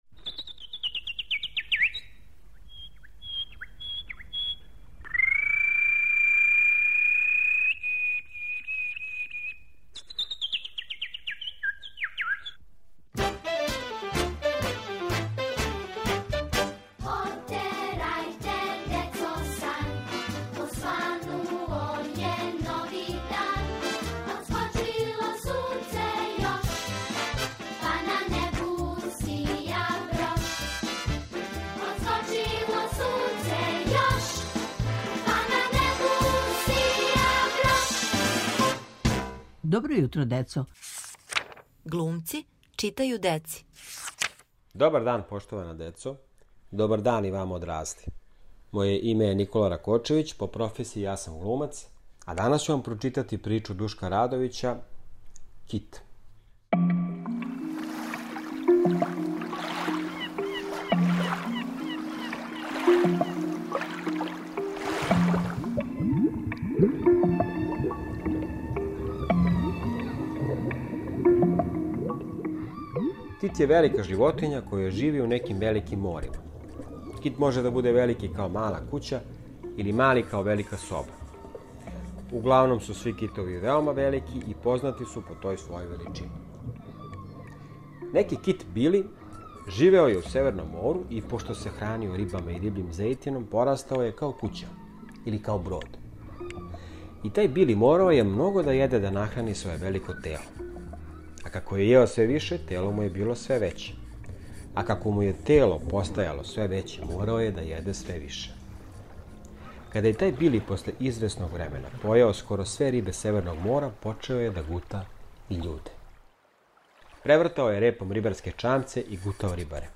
У нашем серијалу "Глумци читају деци", глумац Никола Ракочевић чита вам причу Душка Радовића "Кит".